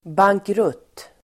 Uttal: [bangkr'ut:]